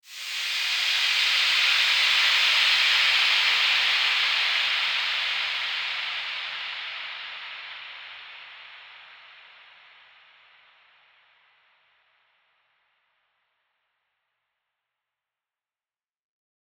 Index of /musicradar/shimmer-and-sparkle-samples/Filtered Noise Hits
SaS_NoiseFilterD-08.wav